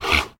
mob / horse / donkey / idle2.ogg